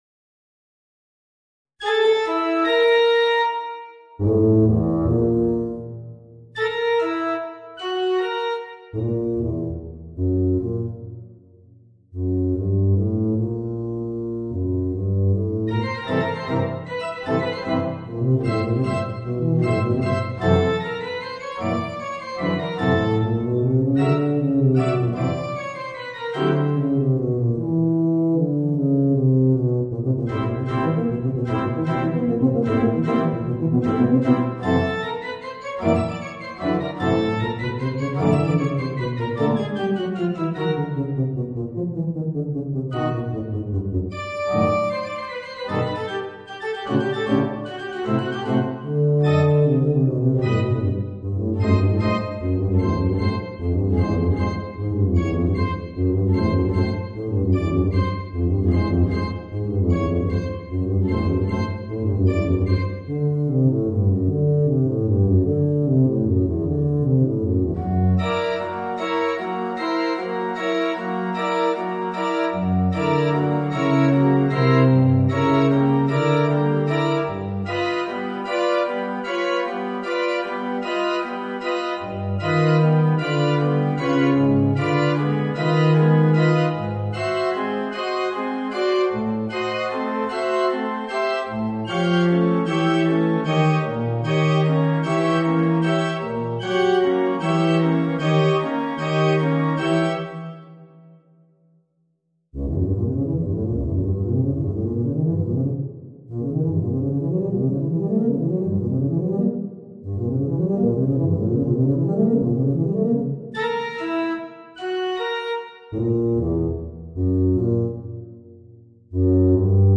Voicing: Eb Bass and Organ